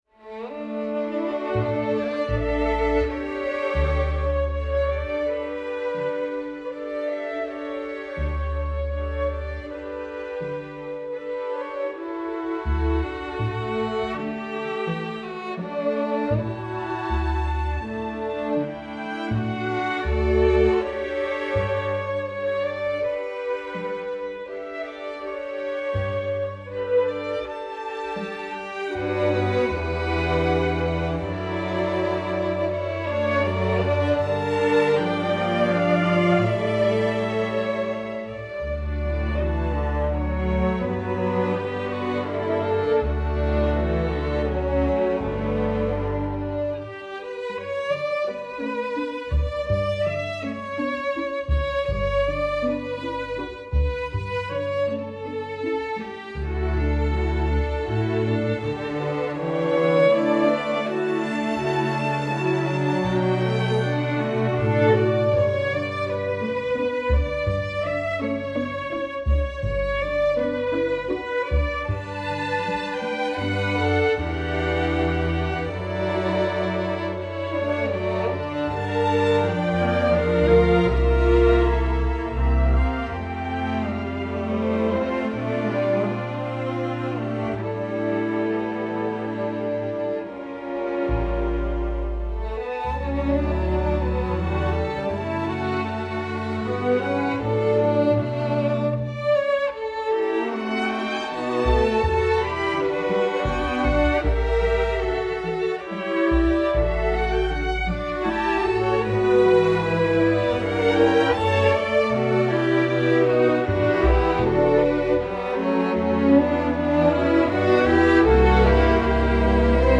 Voicing: String Orchestra